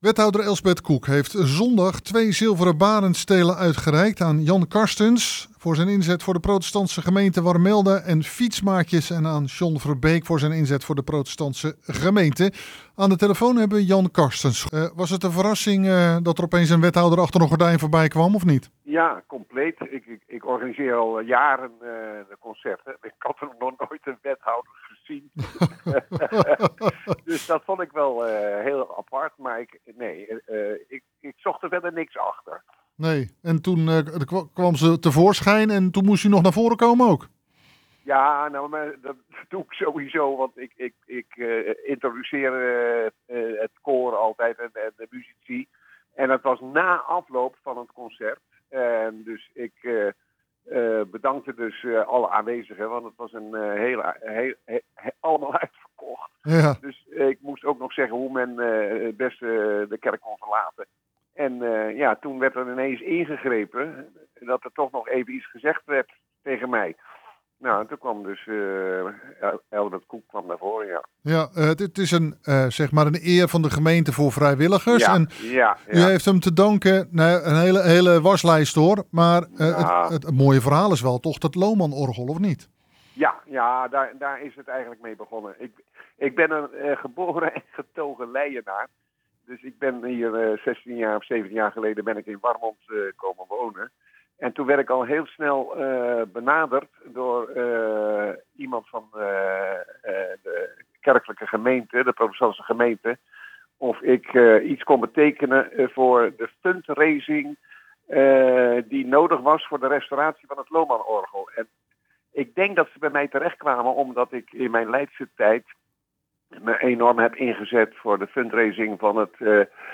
Hieronder het radio-interview